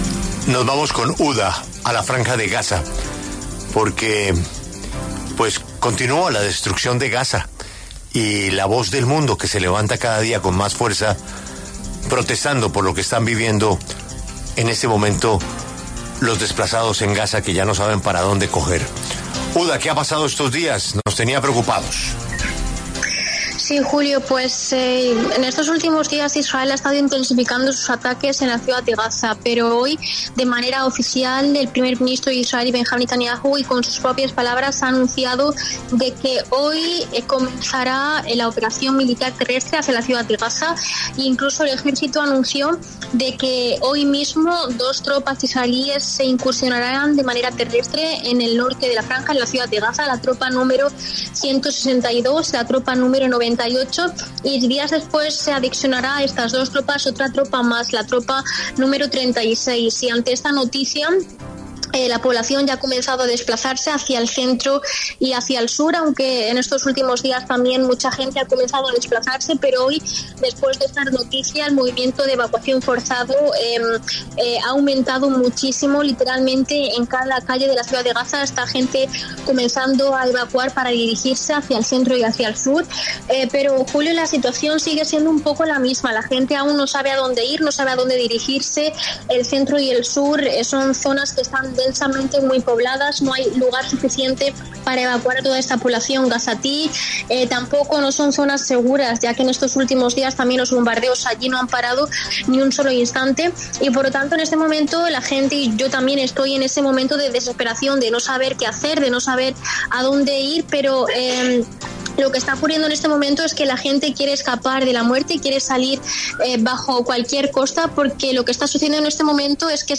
corresponsal en la Franja de Gaza